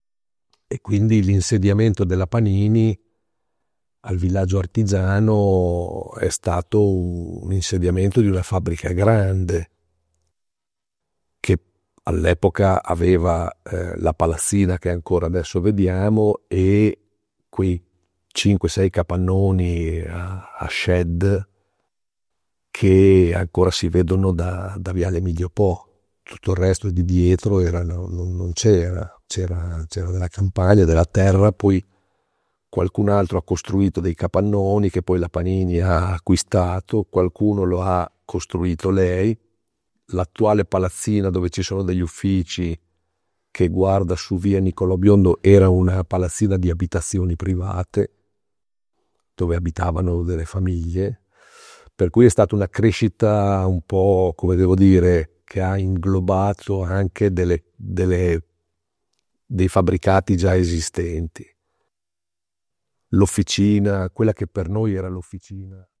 Estratto di intervista